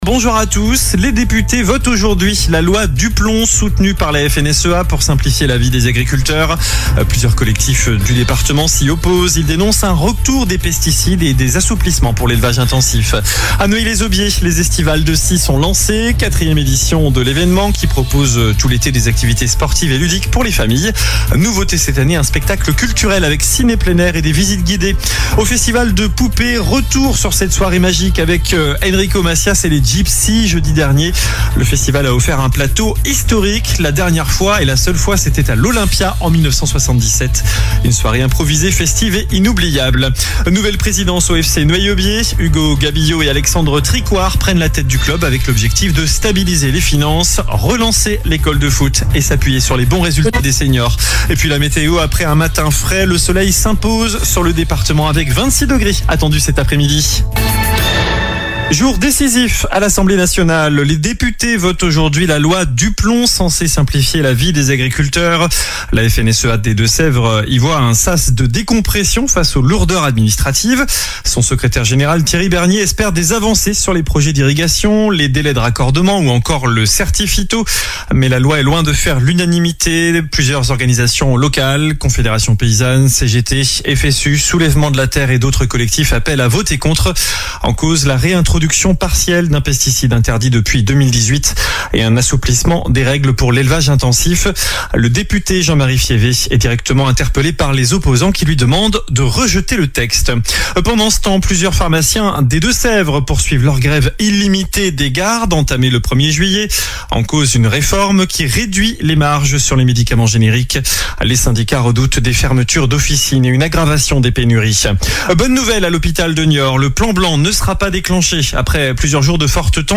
Journal du mardi 8 juillet